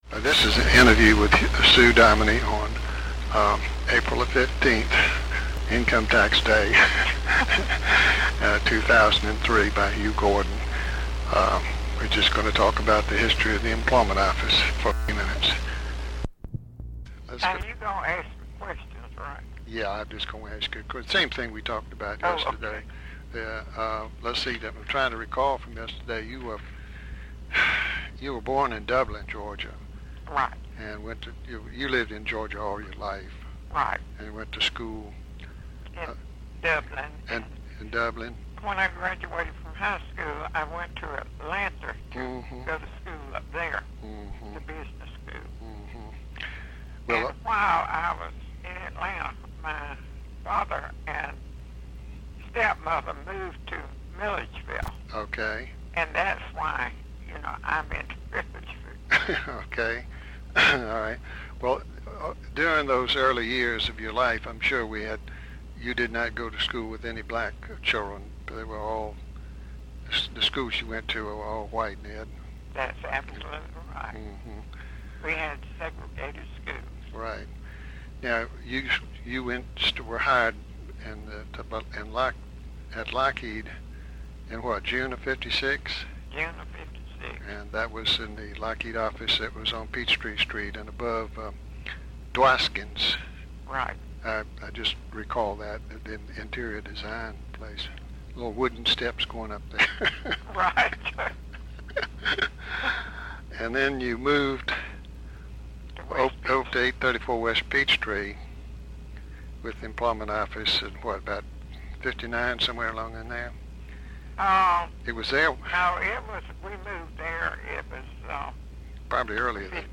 Sound recording digitized from an audiocassette of a telephone interview